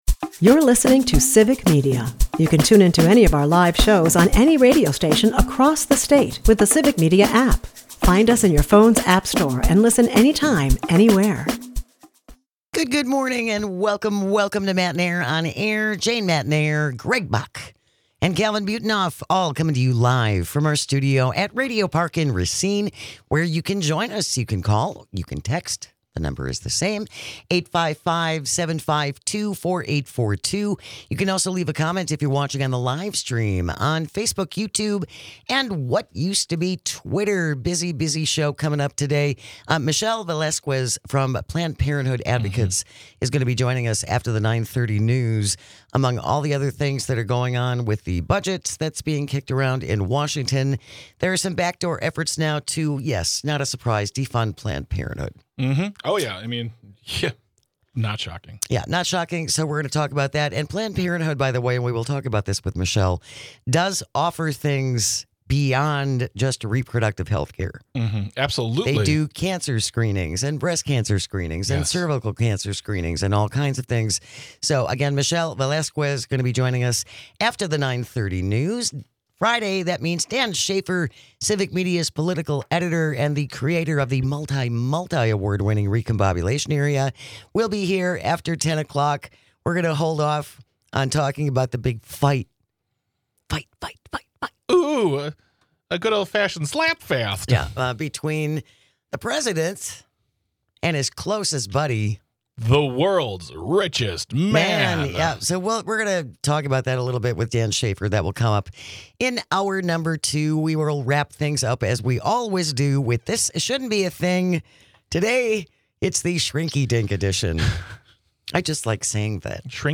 Panel: The Future of Public Education in Wisconsin